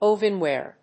アクセント・音節óven・wàre
音節ov･en･ware発音記号・読み方ʌ́v(ə)nwèər